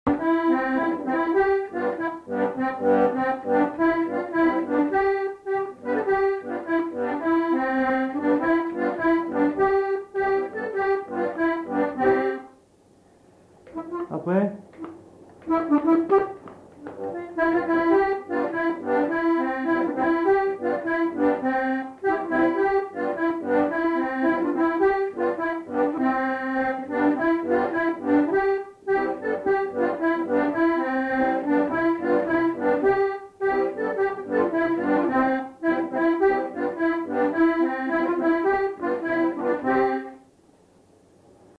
Lieu : Pindères
Genre : morceau instrumental
Instrument de musique : accordéon diatonique
Danse : rondeau